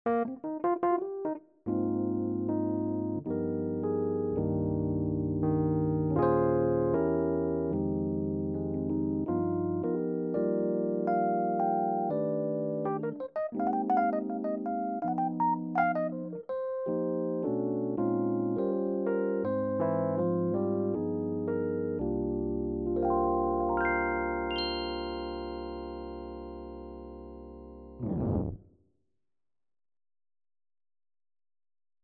Tine Electric Piano